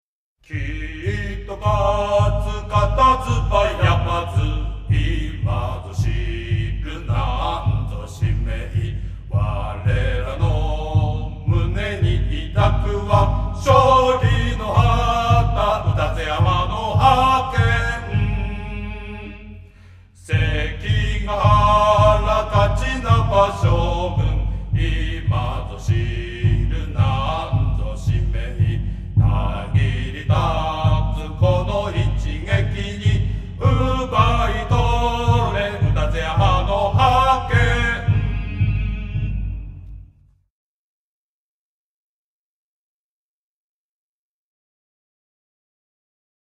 羽咋高校応援歌